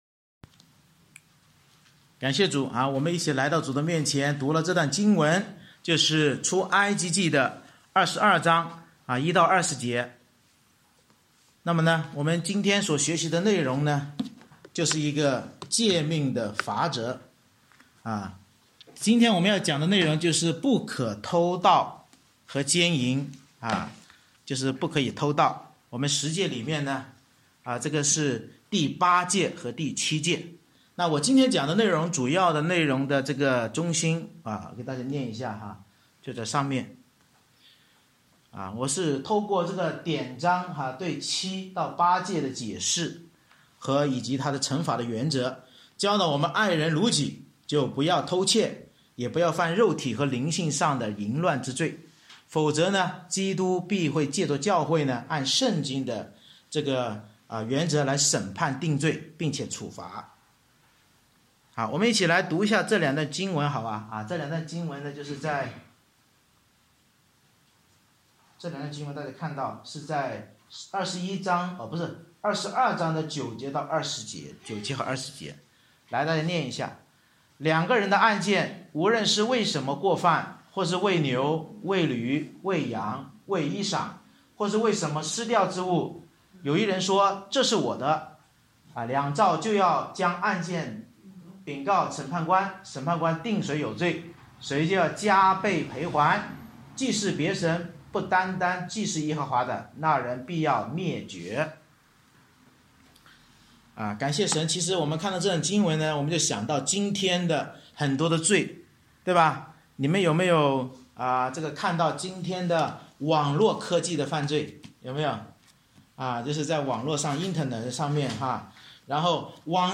Service Type: 主日崇拜